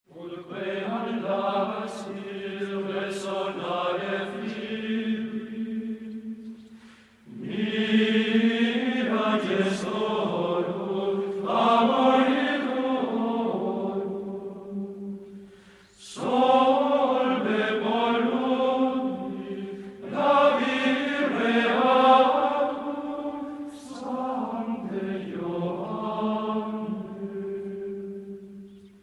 L'hymne célèbre « Ut queant laxis »